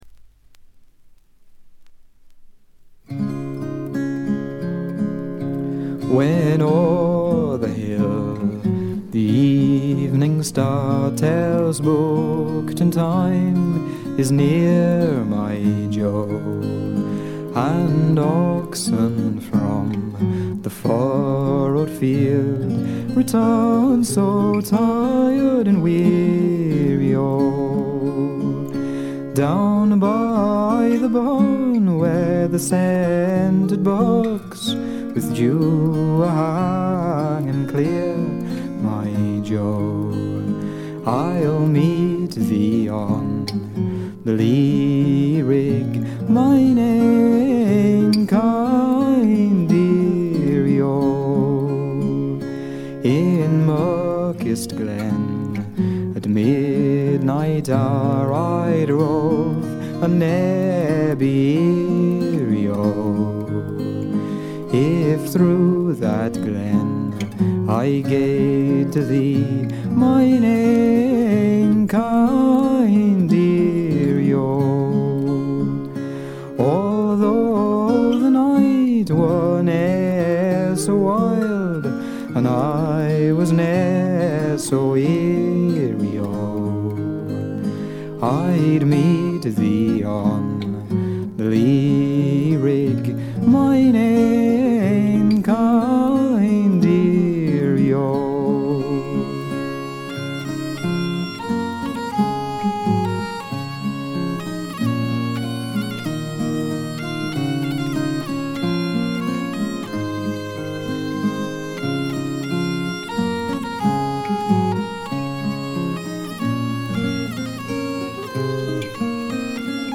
微細なチリプチわずか。
自身のギター、ダルシマー、ツィターの他はコンサーティナ兼笛の奏者が付くだけの極めてシンプルな演奏を聴かせます。
まるで静寂そのものを聴かせるような、静謐で至上の美しさをたたえた作品です。
試聴曲は現品からの取り込み音源です。
Recorded At - Tonstudio St. Blasien